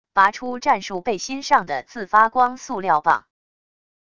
拔出战术背心上的自发光塑料棒wav音频